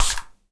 spray_bottle_10.wav